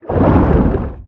Sfx_creature_chelicerate_exoattack_loop_water_os_04.ogg